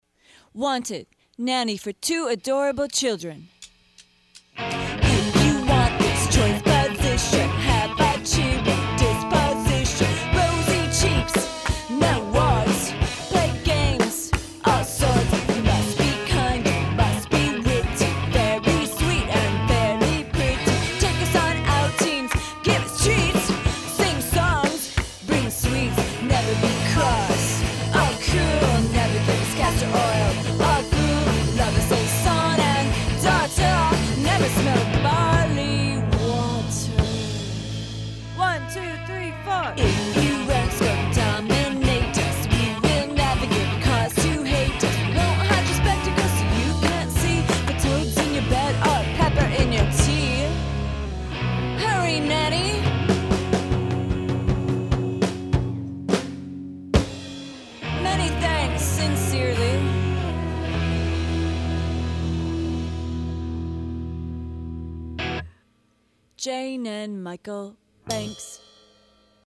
The first is by Chicago girl punk/pop band